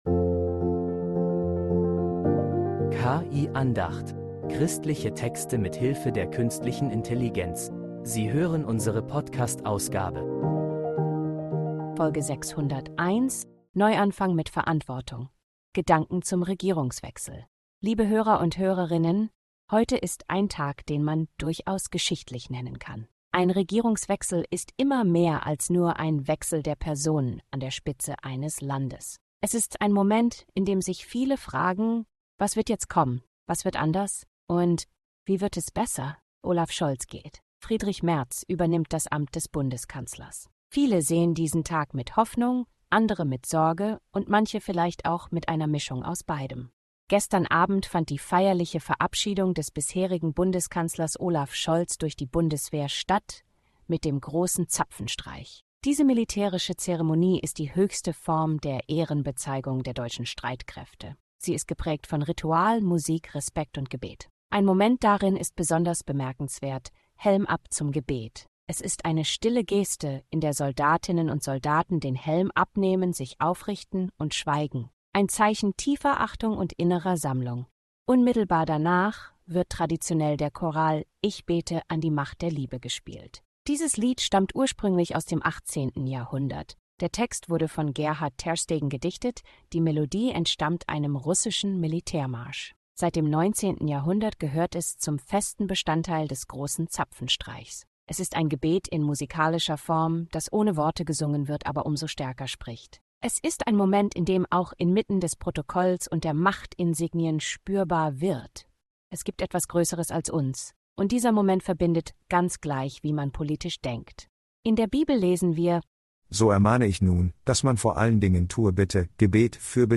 KI-Andacht